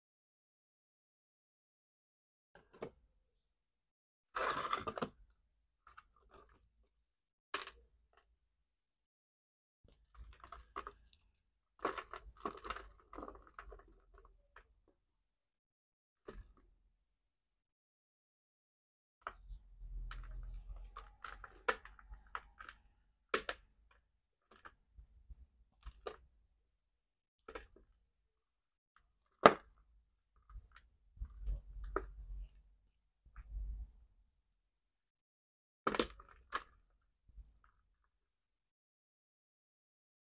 Gym Chalk Crush sound effects free download